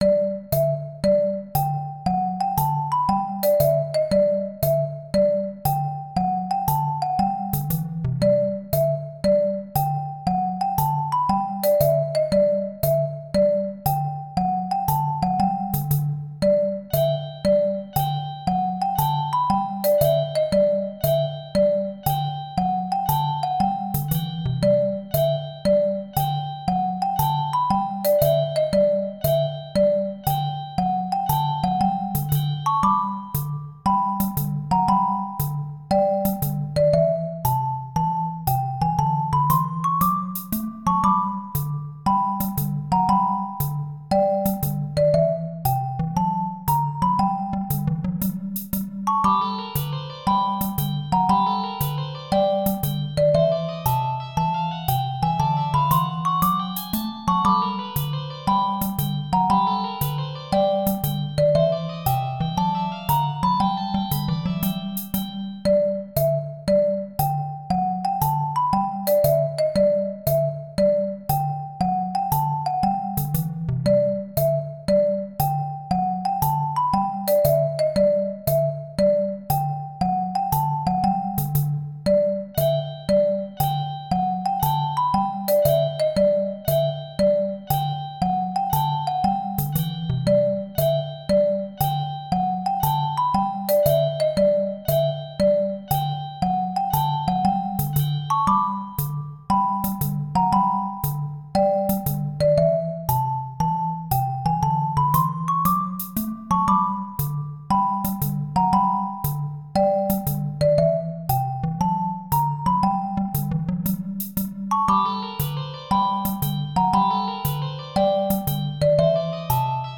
マリンバが足取り軽く散歩道を行きます。
ループ